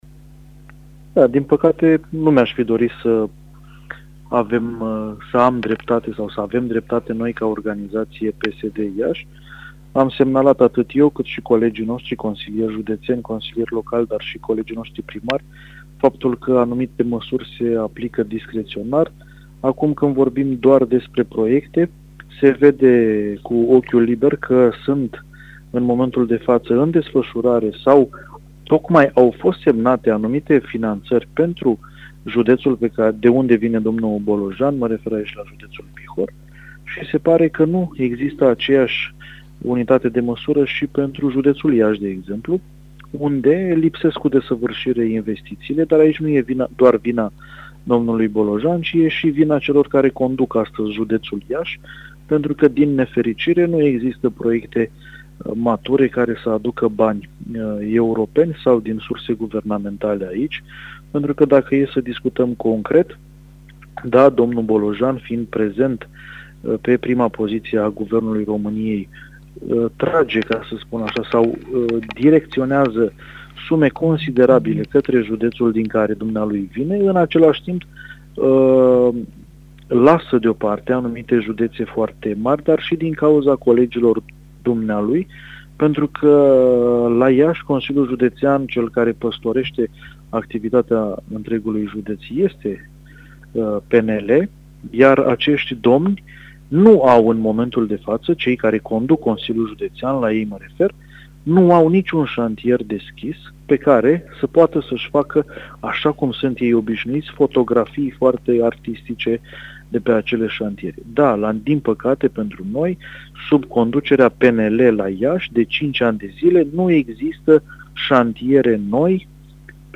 Într-un interviu amplu, acesta vorbește despre efectele „austerității selective”, despre discrepanțele dintre investițiile din Bihor și cele din Iași, dar și despre necesitatea relansării economice.